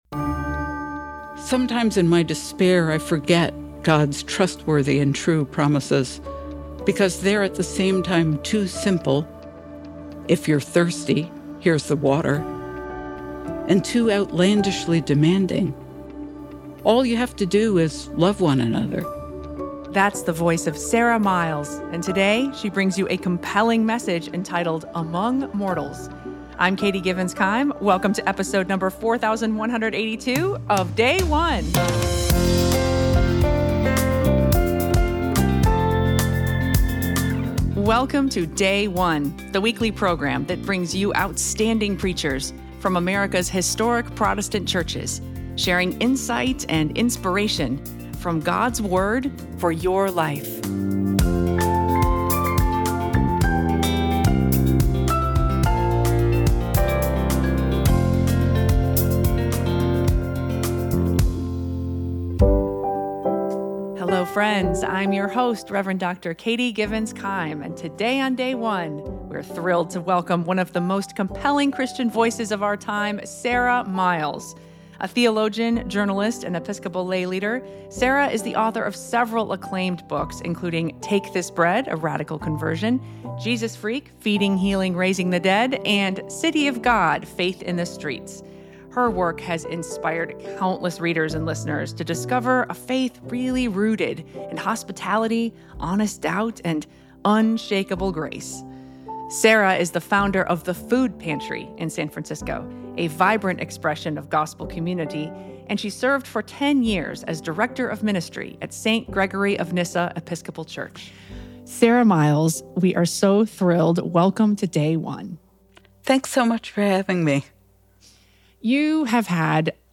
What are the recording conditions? The Episcopal Church 5th Sunday of Easter - Year C Acts 11:1-18